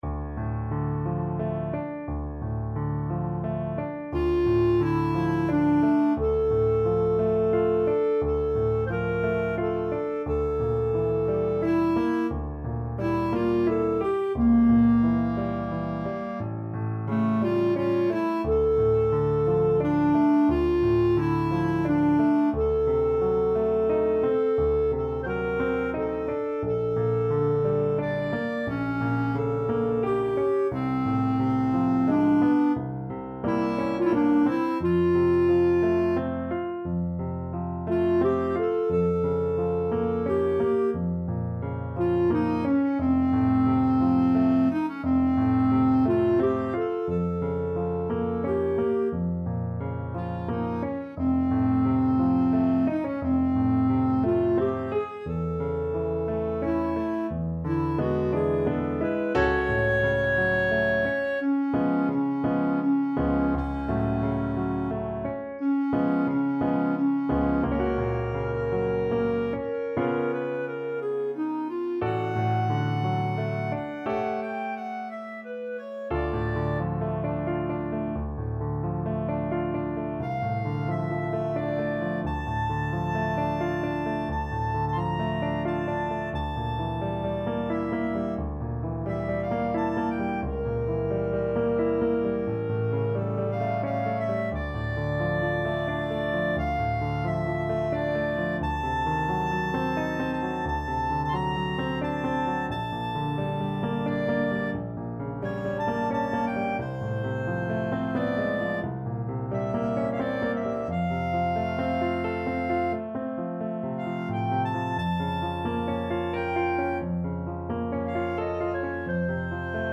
Clarinet
D minor (Sounding Pitch) E minor (Clarinet in Bb) (View more D minor Music for Clarinet )
~ = 88 Malinconico espressivo
3/4 (View more 3/4 Music)
Classical (View more Classical Clarinet Music)
romance-s-169_CL.mp3